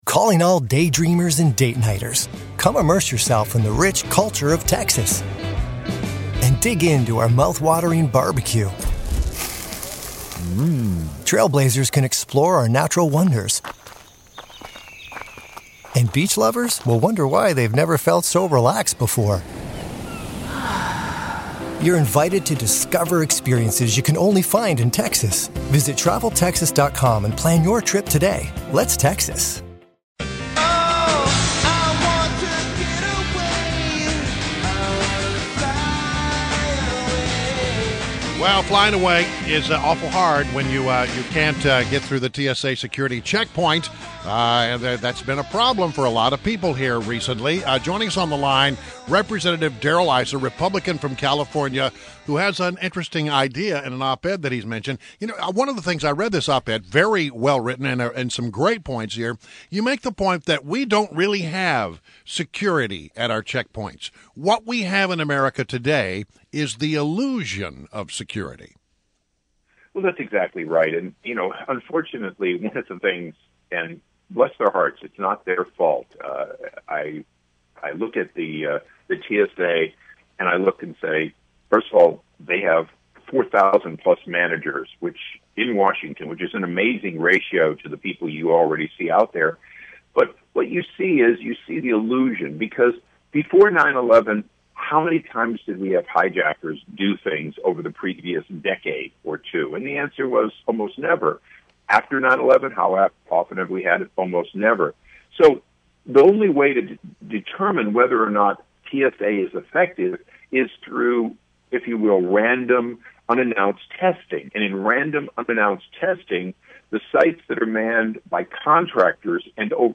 WMAL Interview - REP. DARRELL ISSA 05.25.16
INTERVIEW –CONGRESSMAN DARREL ISSA – U.S. Representative for California’s 49th congressional district, serving since 2001.